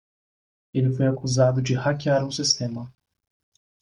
Pronounced as (IPA) /a.kuˈza.du/